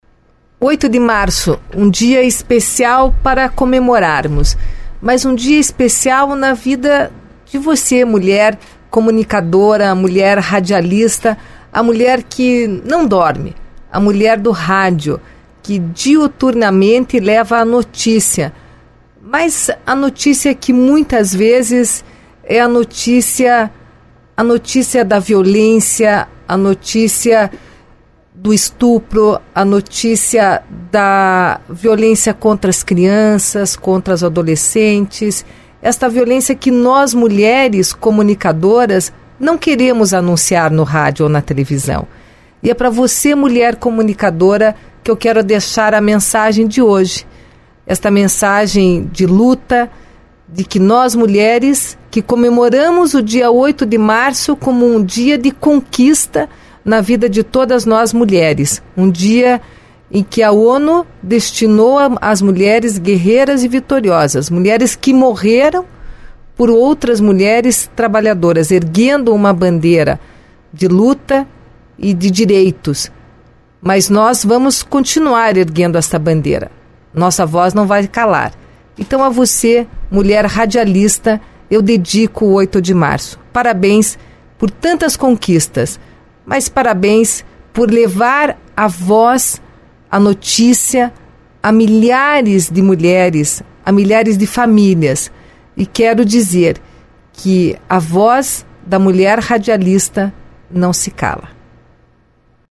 Durante o encontro, a vice-governadora gravou uma mensagem especial à todas as mulheres valorizando suas conquistas. Ressaltou ainda a importância de medidas e campanha de conscientização contra a violência doméstica e a exploração infantil.